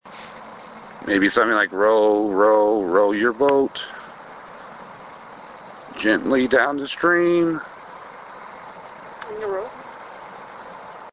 Two Class B EVP's were captured during this mini-investigation.
# 1 Here is the raw segment, not that it is responding to my singing:
Cape_Cemetery_1_Raw_Segment.mp3